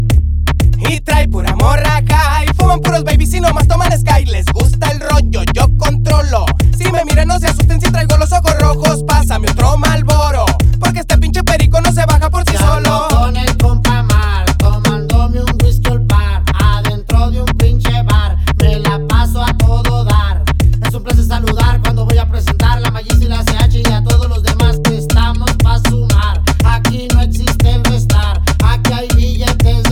# Música Mexicana